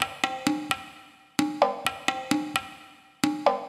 130_perc_3.wav